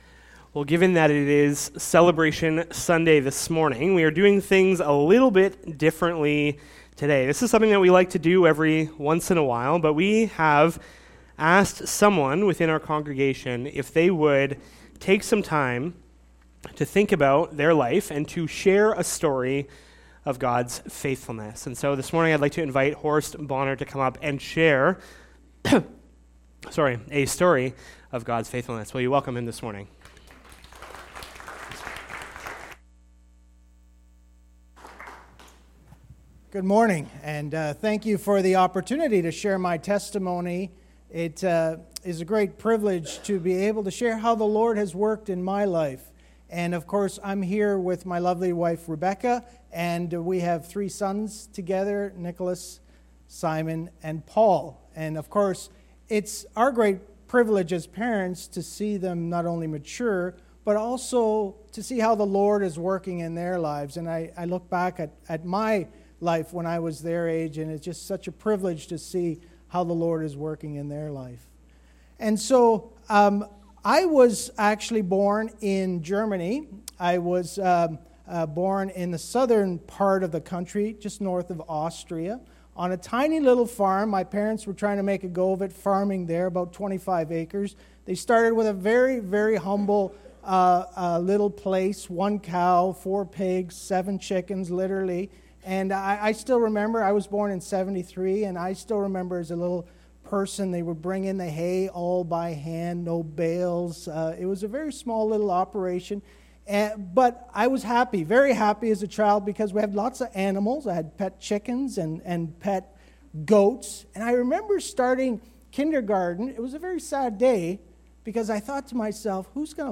Sermon Audio and Video Faith in Action
Celebration Sunday